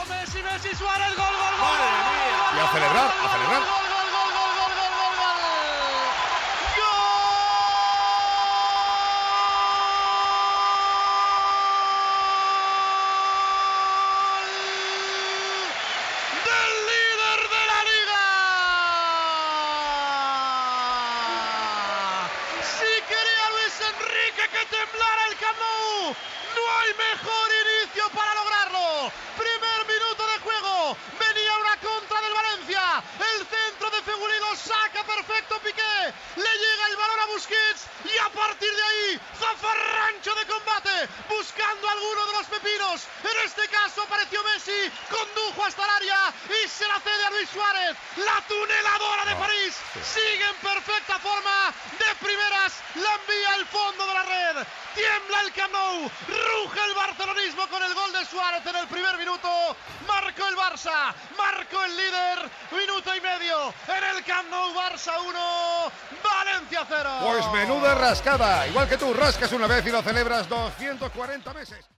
Transmissió del partit de la lliga masculina de futbol entre el Futbol Club Barcelona i el València Club de Futbol.
Narració del gol de Luis Suárez.